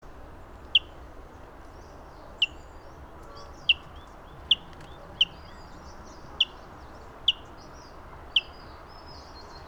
Some Seicercus Warblers in Yunnan, China
2. Call. Recorded 21 April at about 2800 m. This bird was not heard singing, but the call is quite different from that of valentini.